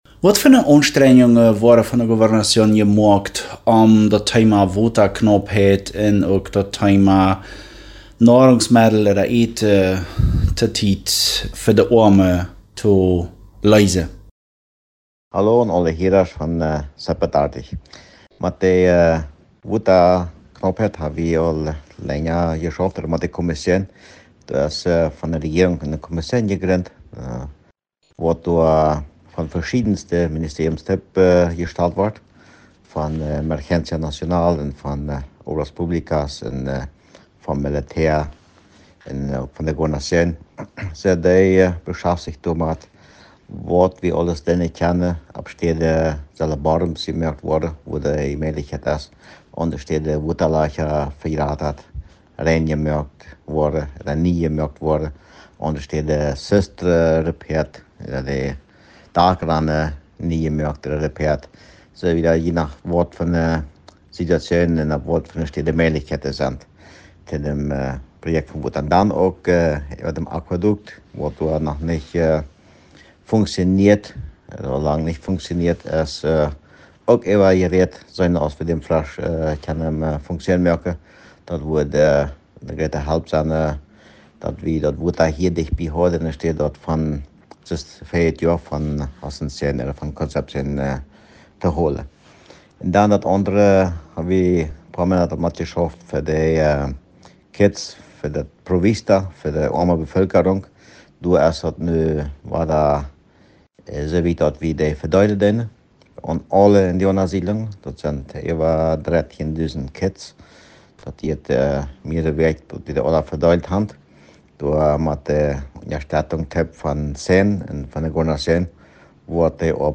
Interview Gobernador Harold Bergen